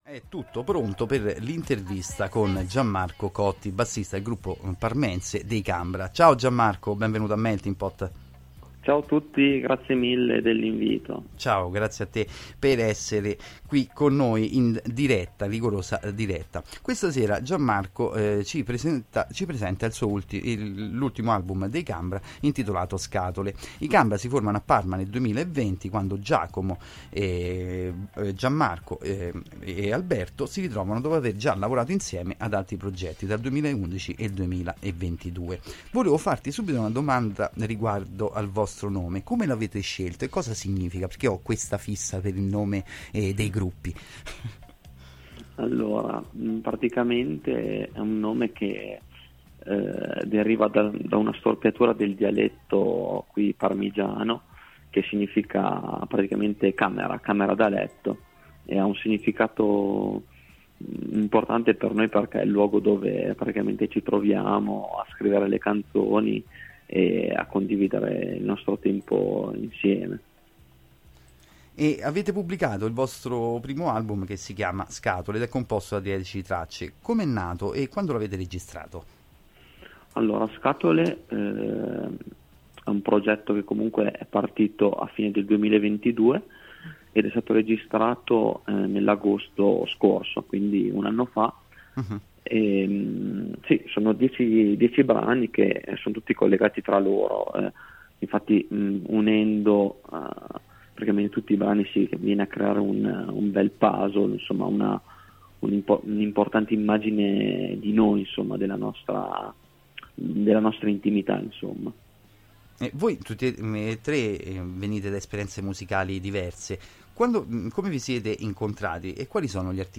Intervista-Cambra-31 Luglio.mp3